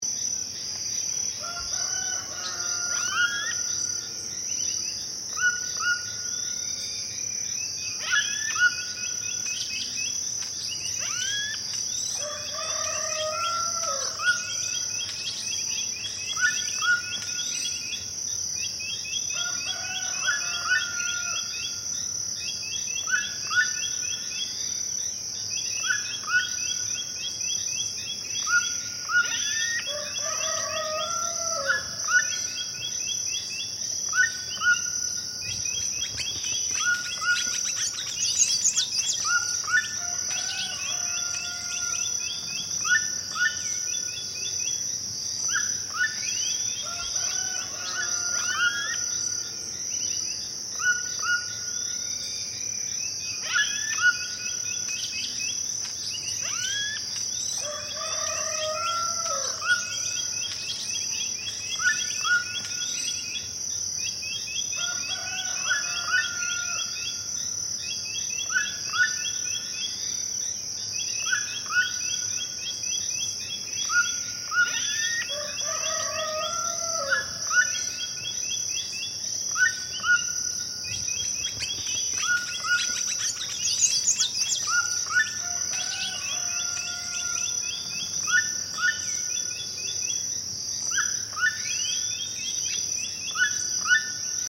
Download Free Insect Sound Effects | Gfx Sounds
Jungle-forest-insects-chirping-and-distant-birds-3.mp3